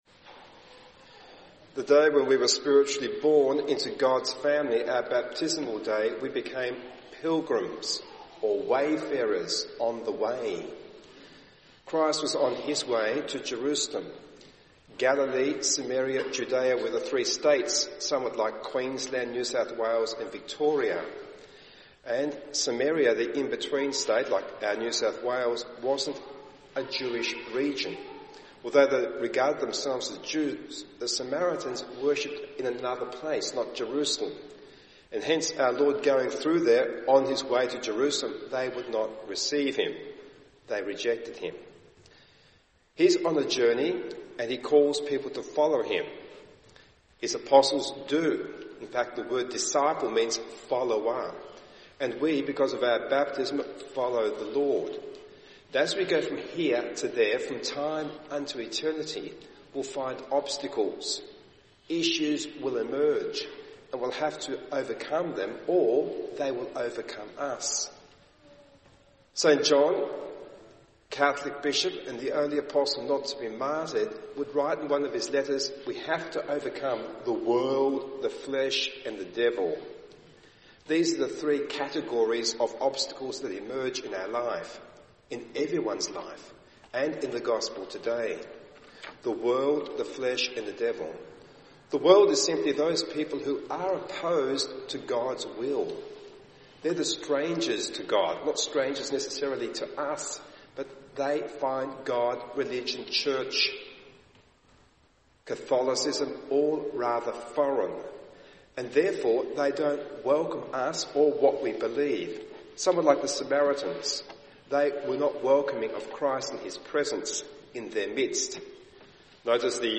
Recorded Homily, Sunday 26th June 2016: